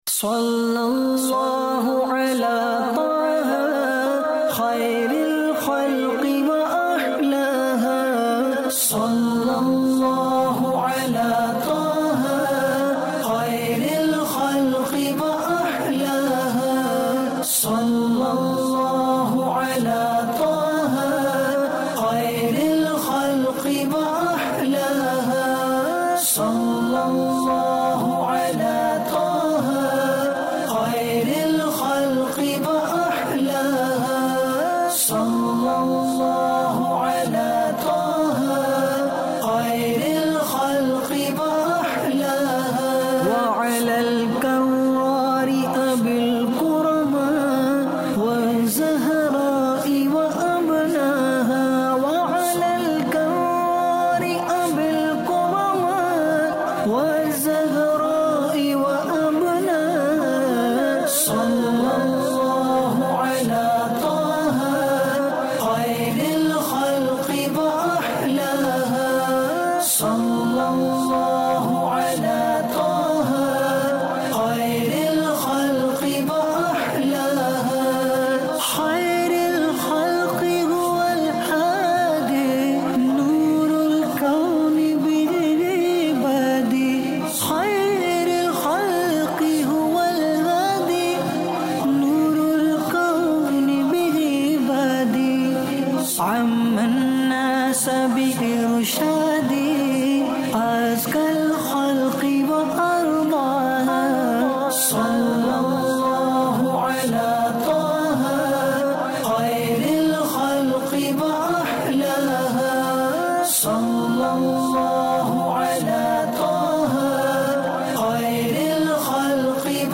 News Clips Urdu